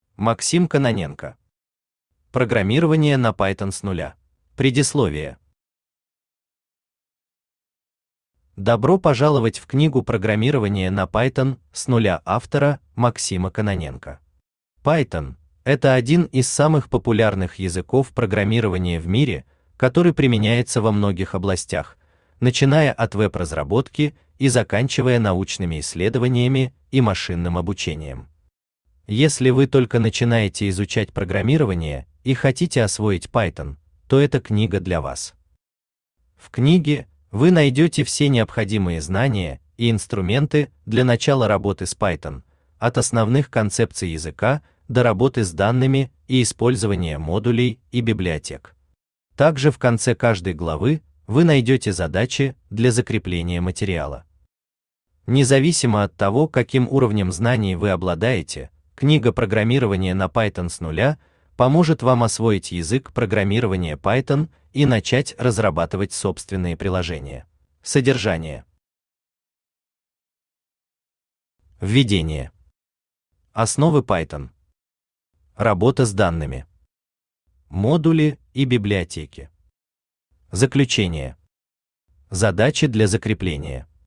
Аудиокнига Программирование на Python с нуля | Библиотека аудиокниг
Aудиокнига Программирование на Python с нуля Автор Максим Кононенко Читает аудиокнигу Авточтец ЛитРес.